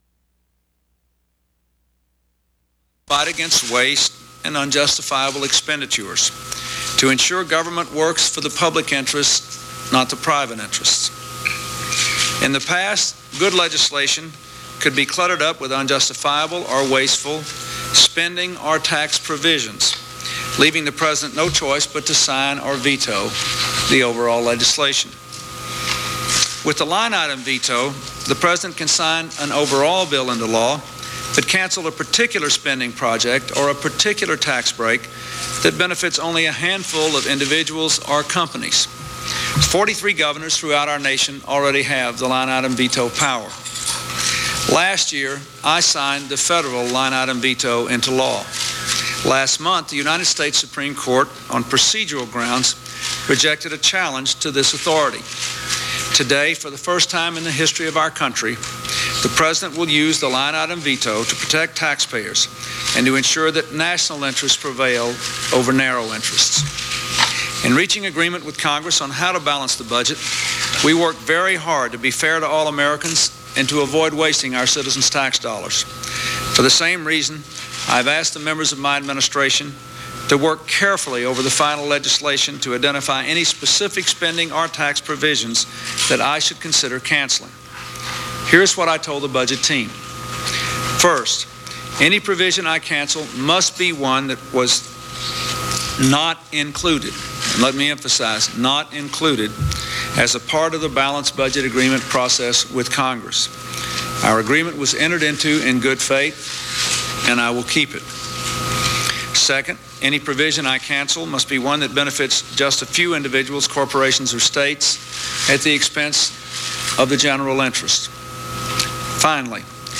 U.S. President Bill Clinton announces the first use of the line item veto